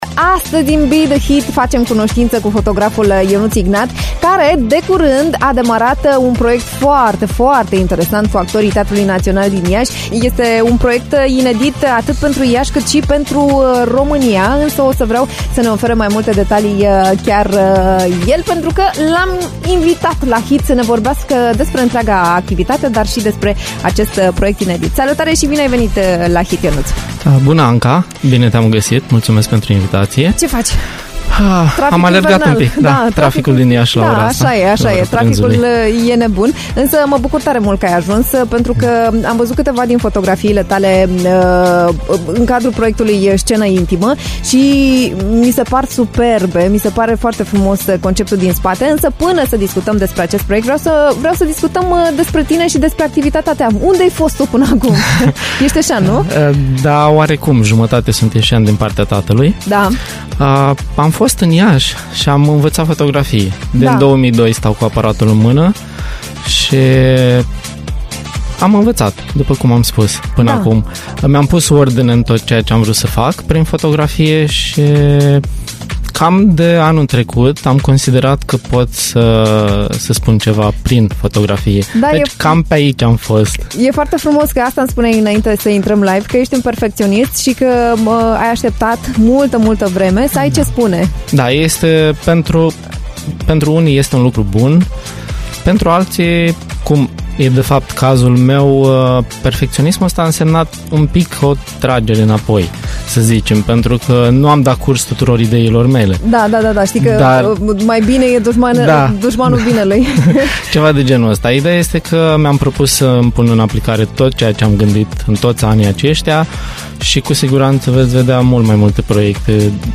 In Be the HIT am stat de vorbă cu